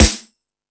Hip House(09).wav